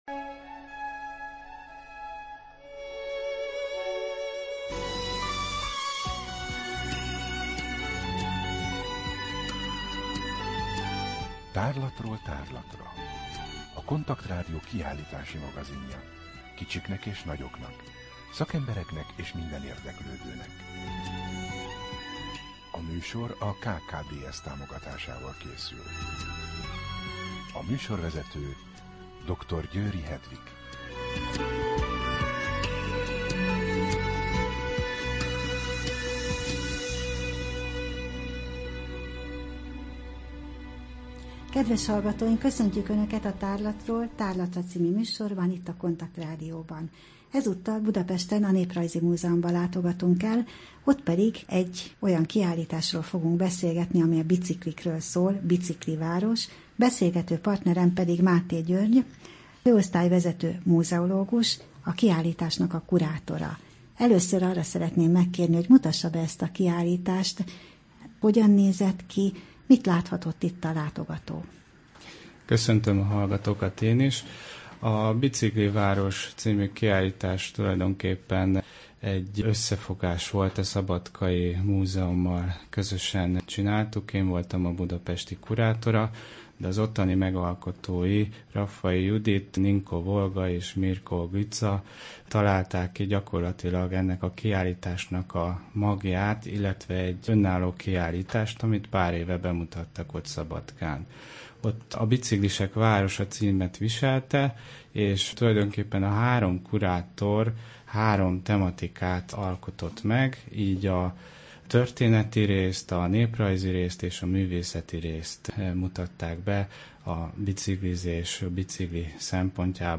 I. Kaleidoszkóp / kiállítási hírek II. Bemutatjuk / Néprajzi Múzeum, Budapest A műsor vendége: